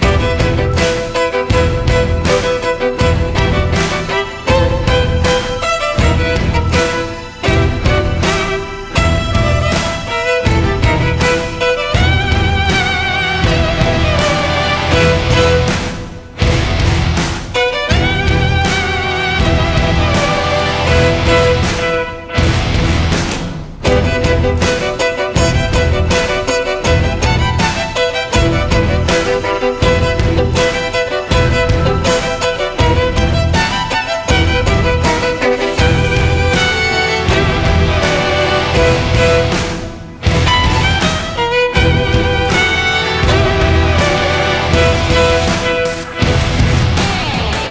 • Качество: 204, Stereo
скрипка
инструментальные
Скрипичная версия известного трека.